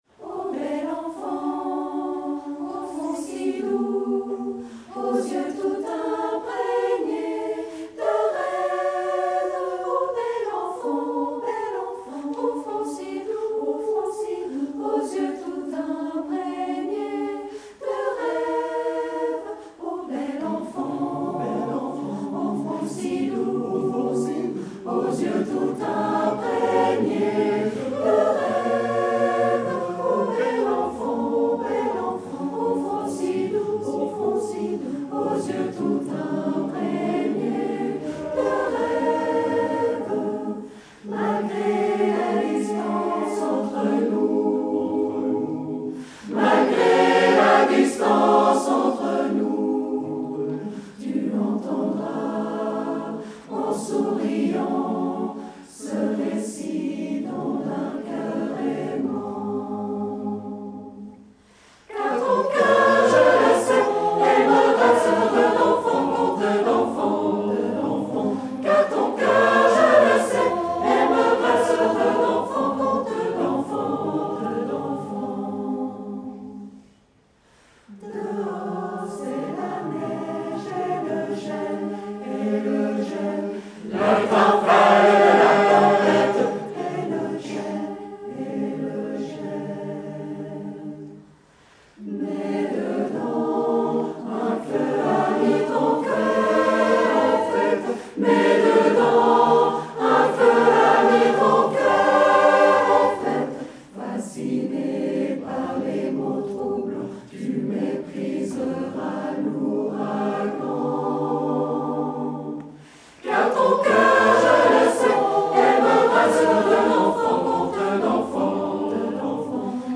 Gland 2023 Fête cantonale des chanteurs vaudois